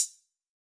Index of /musicradar/ultimate-hihat-samples/Hits/ElectroHat C
UHH_ElectroHatC_Hit-22.wav